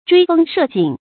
追風攝景 注音： ㄓㄨㄟ ㄈㄥ ㄕㄜˋ ㄐㄧㄥˇ 讀音讀法： 意思解釋： 見「追風躡景」。